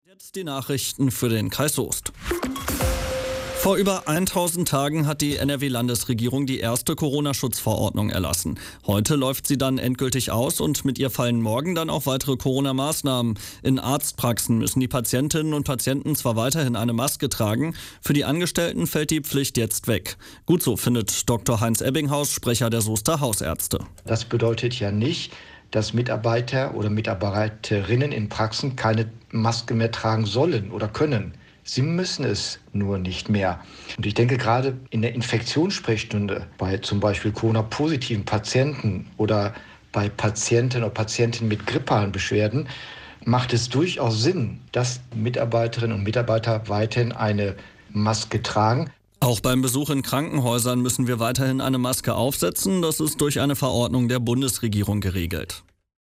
06:30 Uhr - Nachricht: Ende der Coronaschutzverordnung